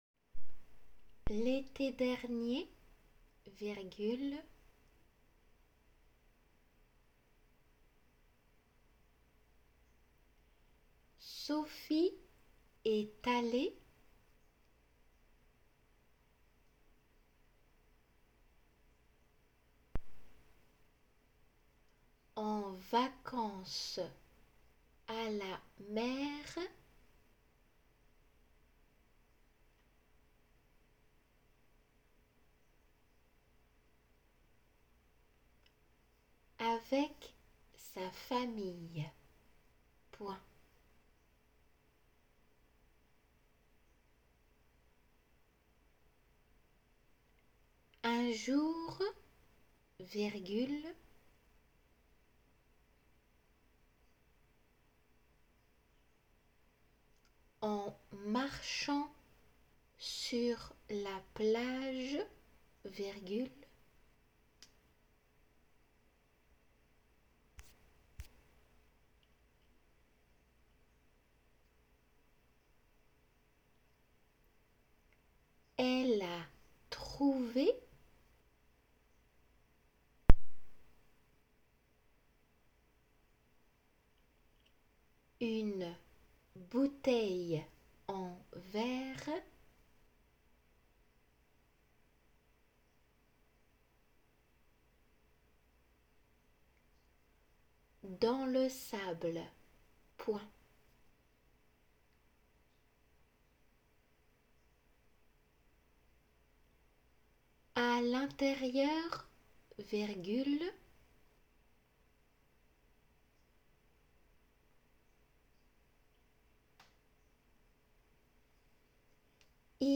仏検　2級　デイクテ　音声ー秋 5